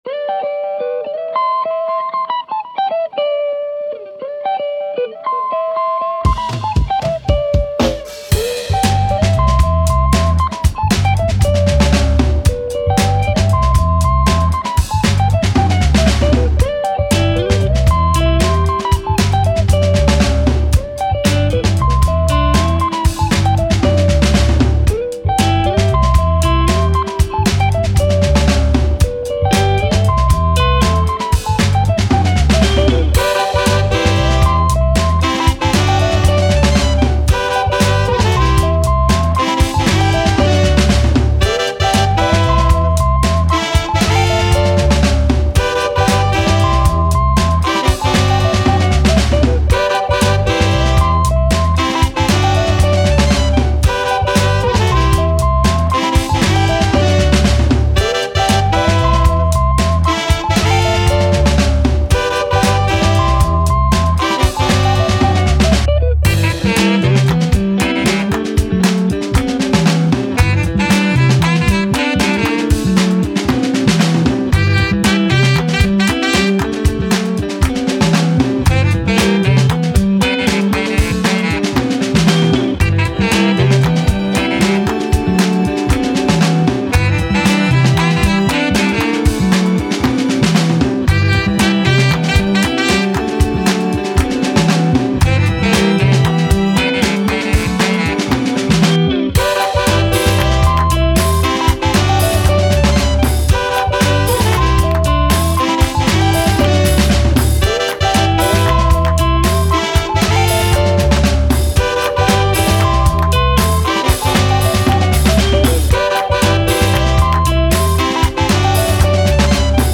Afrobeat, Action, Positive, Upbeat, Joy